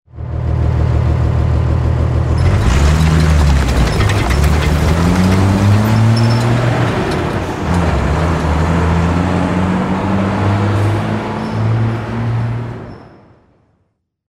Salida de un autobús antiguo
arranque
autobús
Sonidos: Transportes